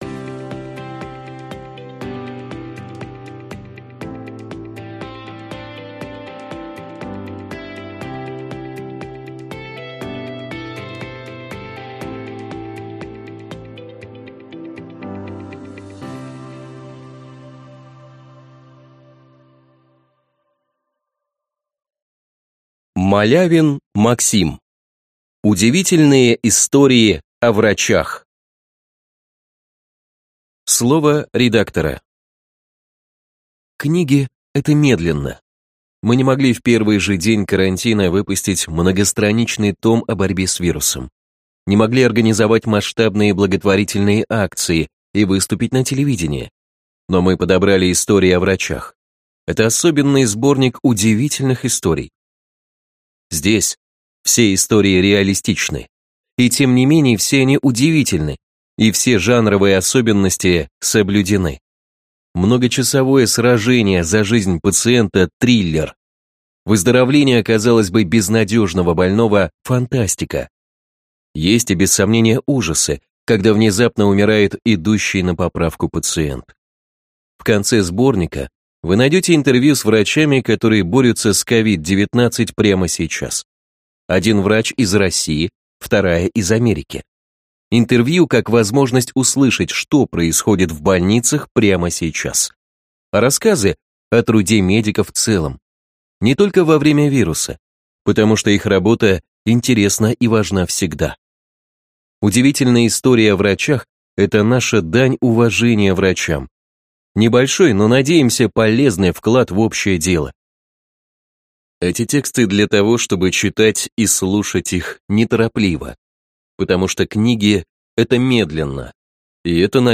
Аудиокнига Удивительные истории о врачах | Библиотека аудиокниг